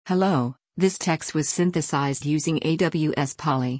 But if you ask me, the output often sounded artificial, not like human speech at all.
Listen to an example from AWS Polly and you'll see what I mean.
aws-polly.mp3